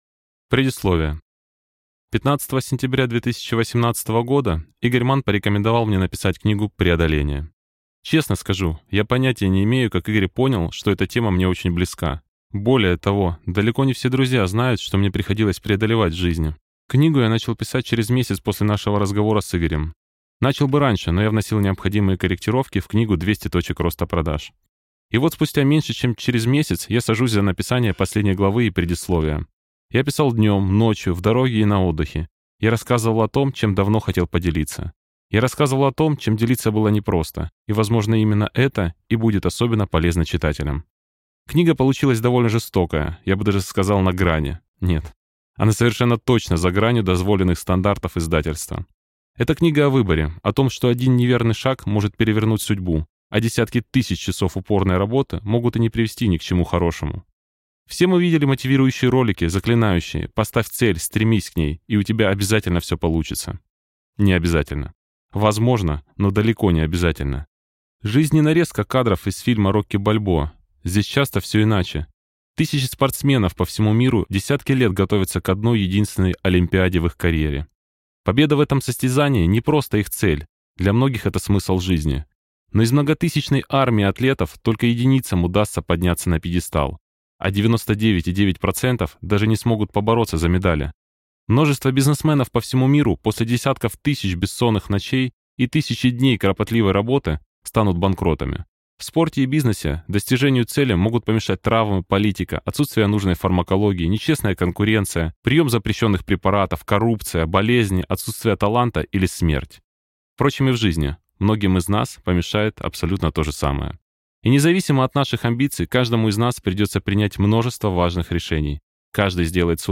Aудиокнига Преодоление